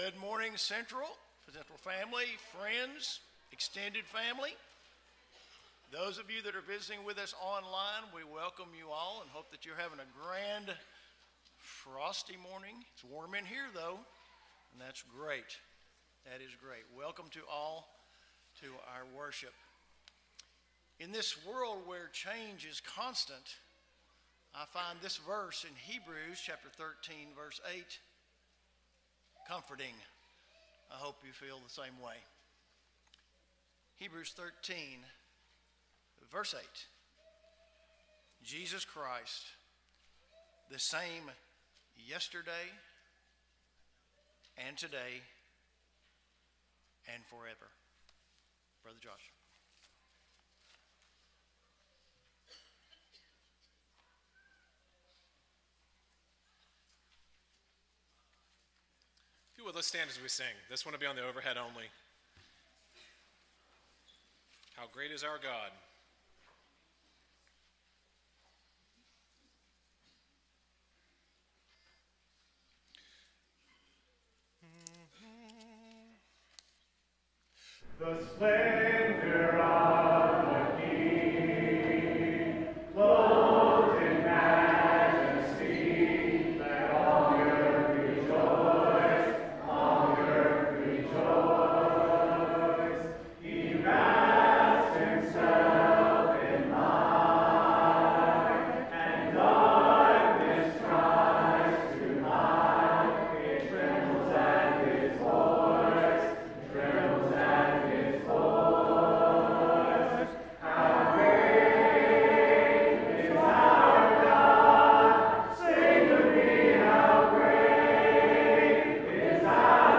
Luke 19:10, English Standard Version Series: Sunday AM Service